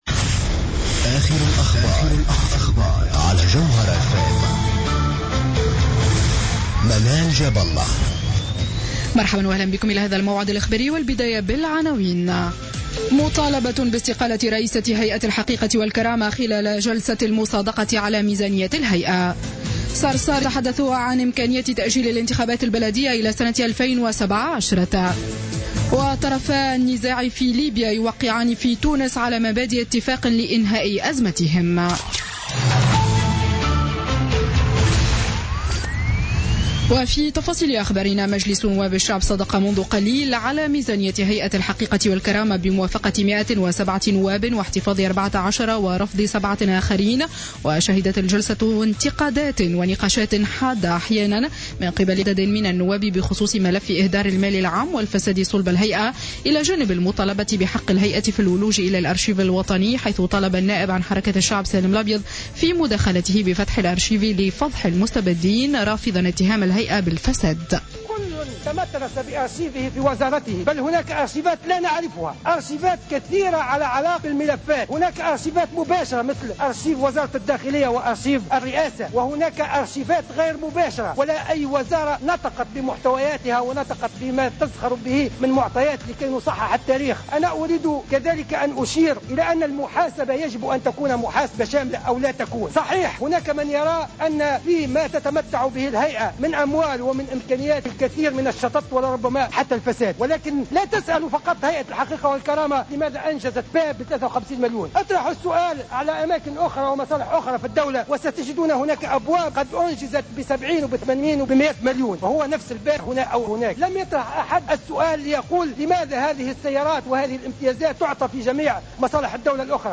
نشرة أخبار السابعة مساء ليوم الأحد 6 ديسمبر 2015